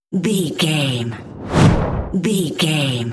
Whoosh deep fast
Sound Effects
Fast
dark
futuristic
intense